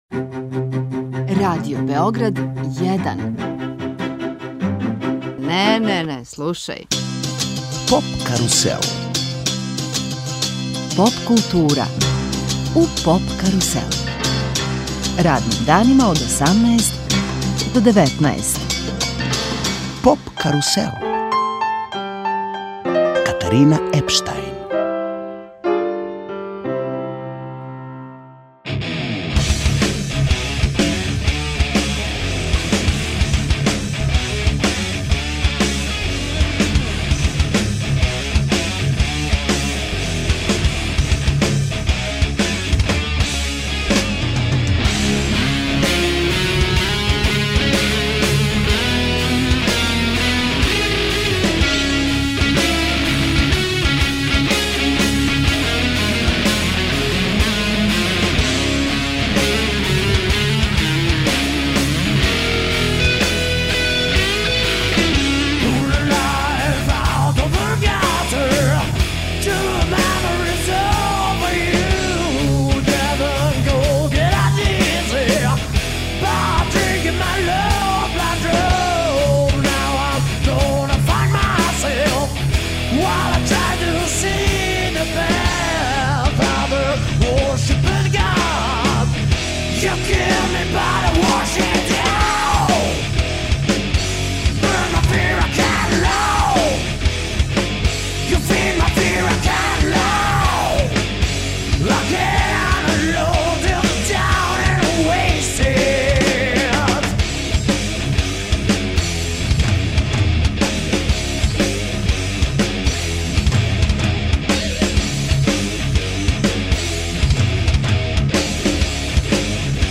Звук бенда се темељи на мешавини разних гитарских стилова, али најприближнији жанр је Southern Stoner Grunge.